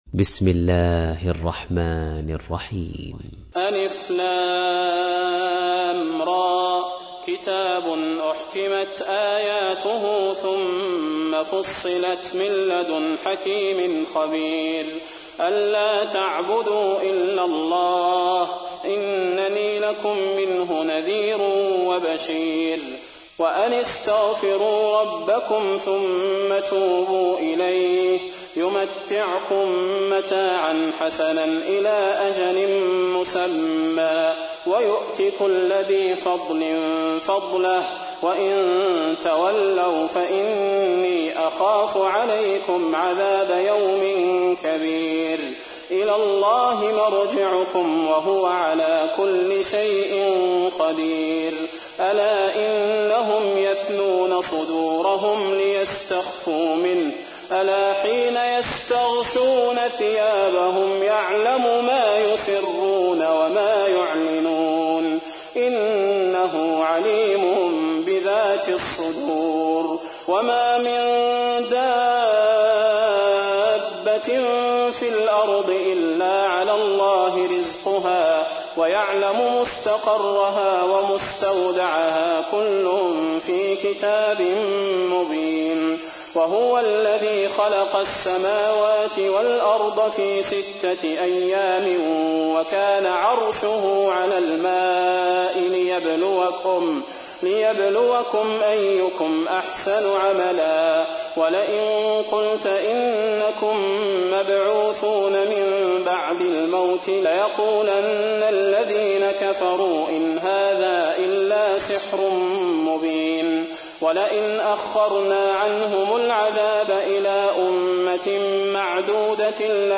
تحميل : 11. سورة هود / القارئ صلاح البدير / القرآن الكريم / موقع يا حسين